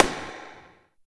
51 SNARE 5-R.wav